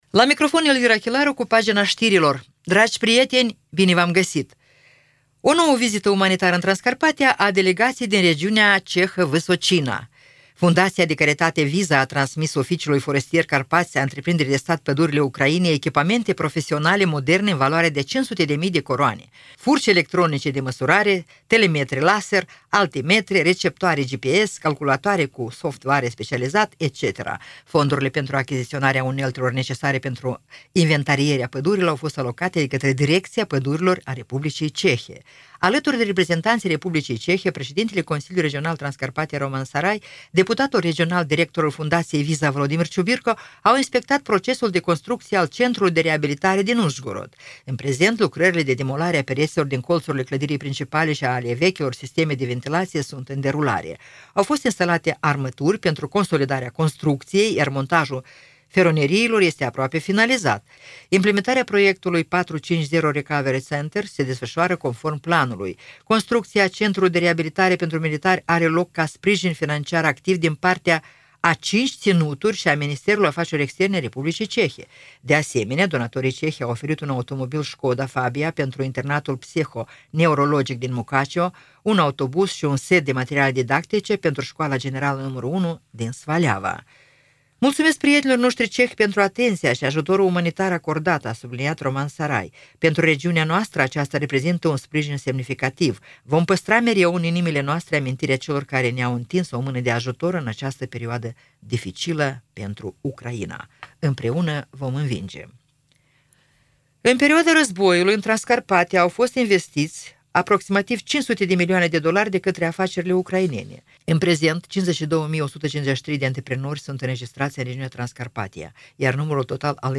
Știri Radio Ujgorod – 12.11.2024